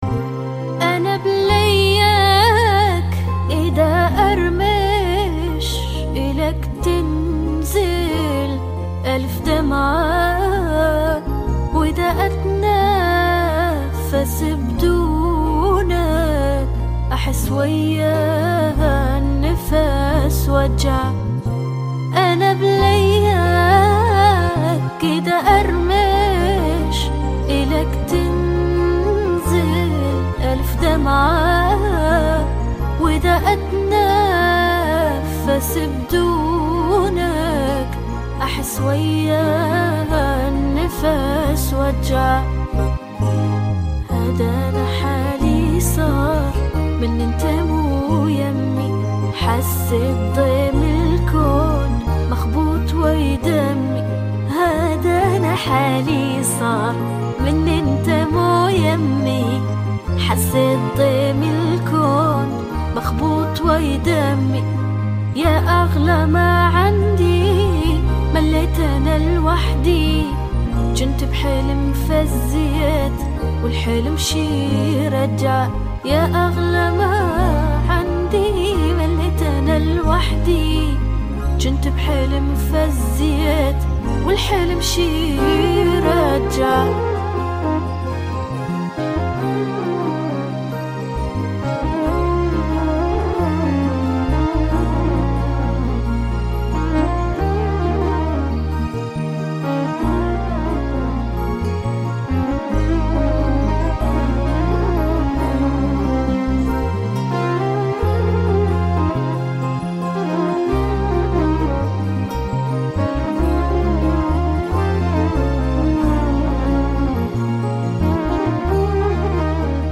بيانو